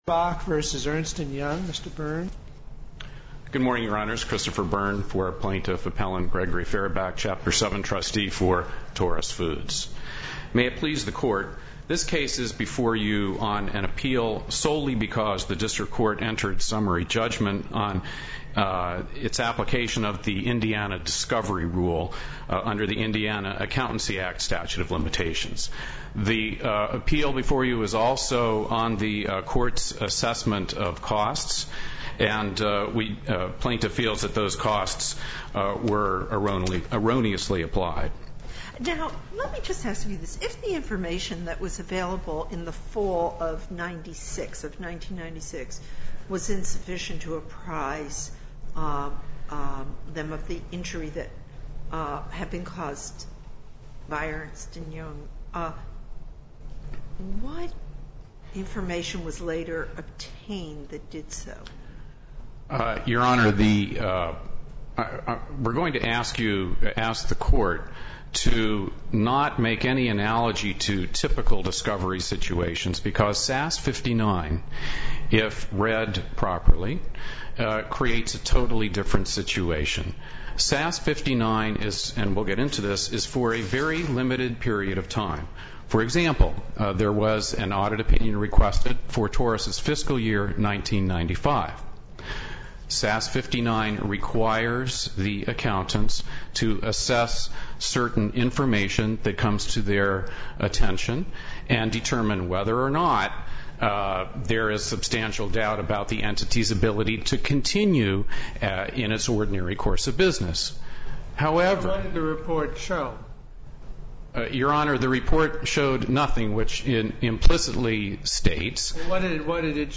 Those interested in gaining insights into Judge Posner’s views on the scope of an auditor’s responsibilities in connection with the issuance of a going-concern opinion are well-advised to listen to his pointed questions (and his demand for pointed answers) in the 40 minute oral argument in the case.